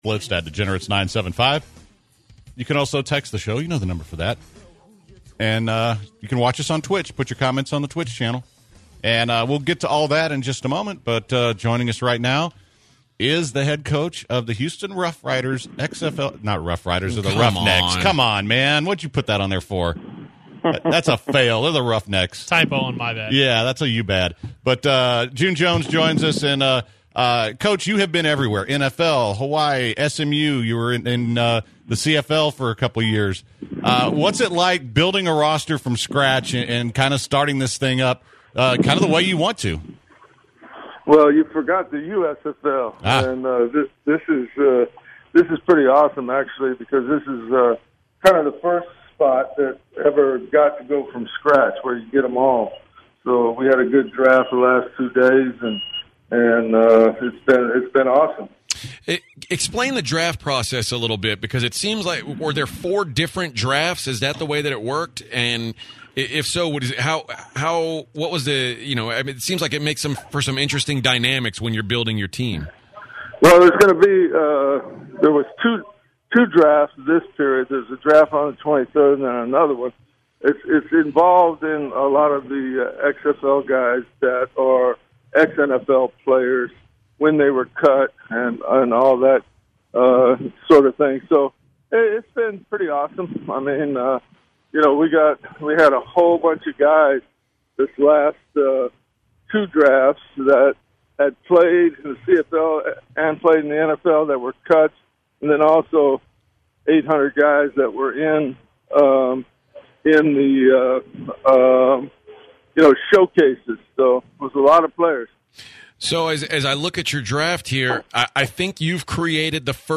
Interview with XFL Houston Roughnecks Head coach June Jones.